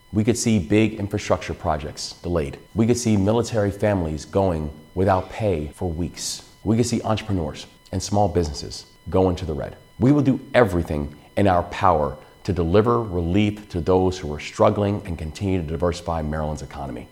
Governor Wes Moore delivered a special address to the people of Maryland in the wake of the federal government shutdown.